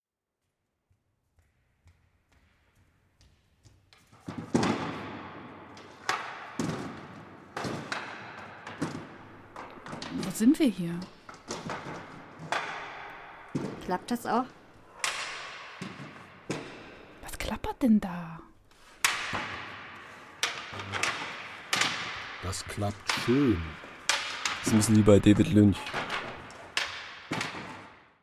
Gleichzeitig lief in der restaurierten Taufkapelle unser Hörspiel ‚Zwischen allen Stühlen‘, das auch zum Leipziger Hörspielsommer eingereicht wurde. In einer Collage aus 12 kurzen Szenen geht es um die kleinen und großen Wahrheiten des Alltags und manchmal auch einfach nur um die übermächtige Aufgabe, ein Kirchenschiff von 700 Klappstühlen frei zu räumen.